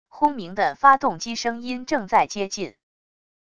轰鸣的发动机声音正在接近wav音频